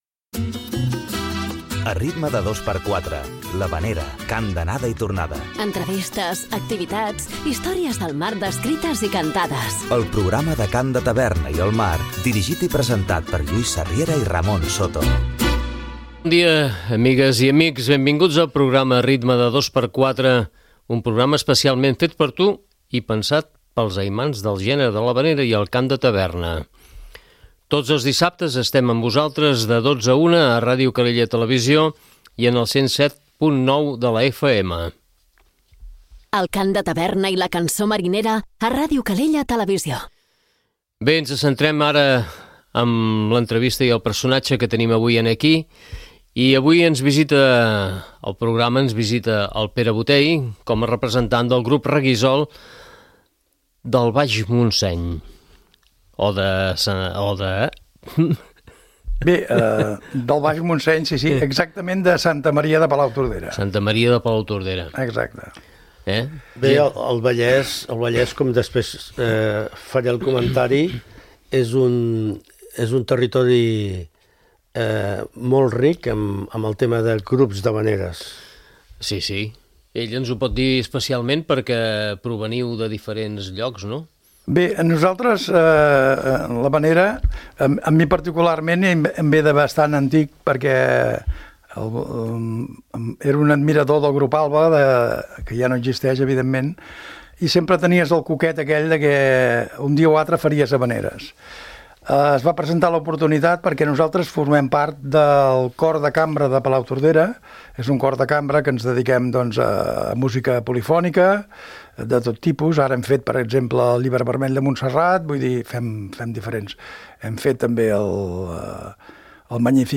A l’espai “A Contratemps” escoltarem algunes de les seves cançons.